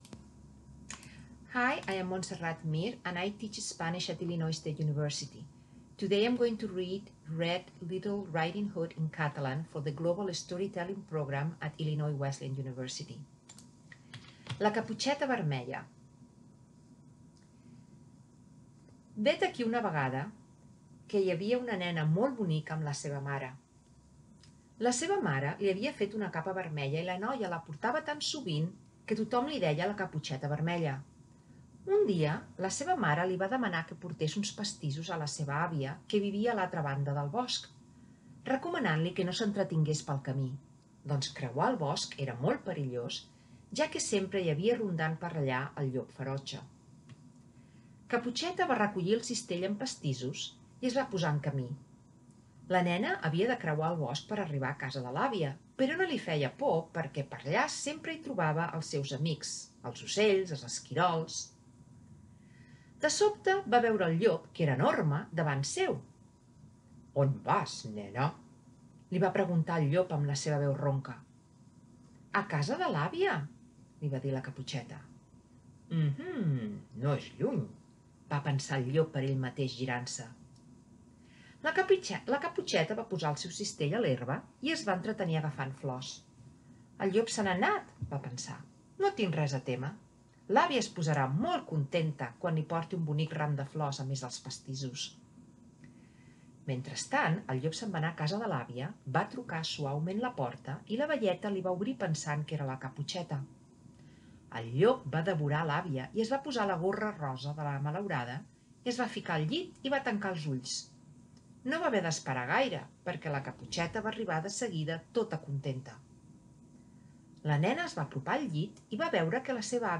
Barcelona, Spain